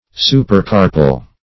Meaning of supercarpal. supercarpal synonyms, pronunciation, spelling and more from Free Dictionary.
Search Result for " supercarpal" : The Collaborative International Dictionary of English v.0.48: Supercarpal \Su`per*car"pal\, a. (Anat.) Situated above, or in the upper part of, the carpus.